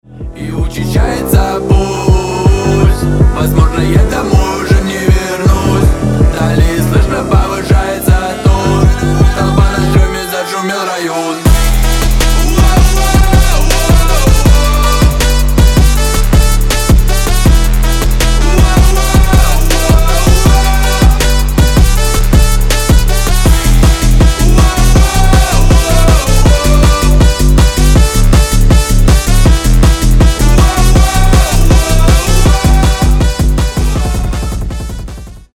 • Качество: 320, Stereo
мощные басы
Trap
качающие